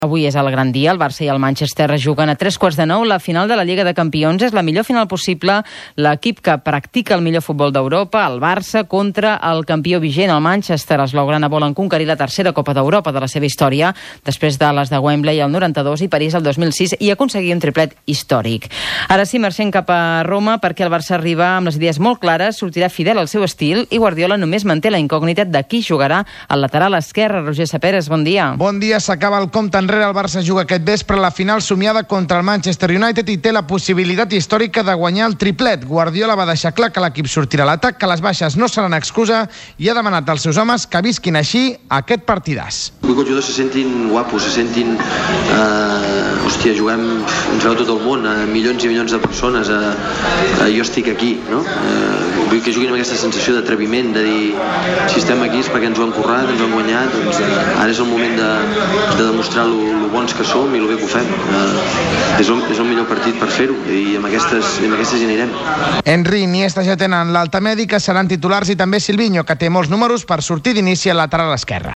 f4b7a5e4a641f7f04d342c7188be6c768e477255.mp3 Títol RAC 1 Emissora RAC 1 Barcelona Cadena RAC Titularitat Privada nacional Nom programa El món a RAC 1 Descripció Roda de premsa de Guardiola a la final de la Lliga de Campions de Roma. Gènere radiofònic Esportiu